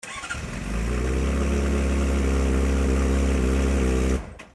モリワキマフラーのアイドリング音(一次側二次側消音)
純正マフラーよりも約6dBほど音圧を落とすことが出来ました。